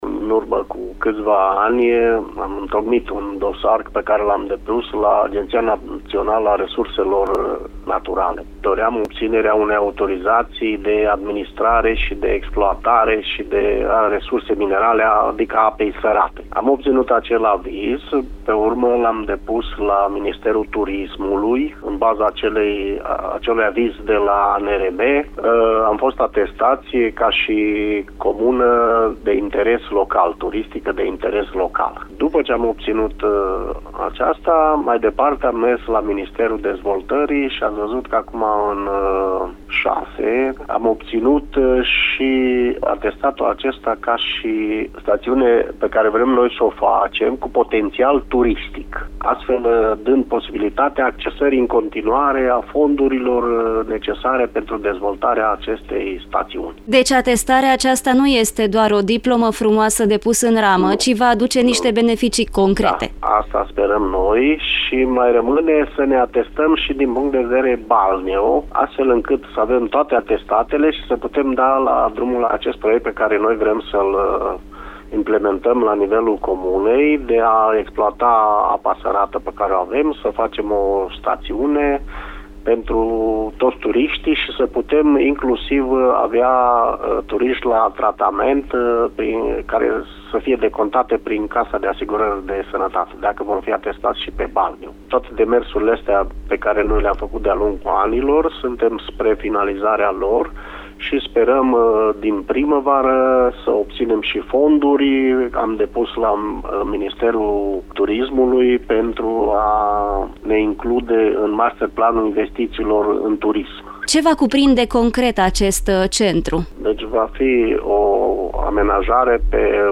Primarul comunei, Dorel Pojar, explică pașii care au dus la această atestare: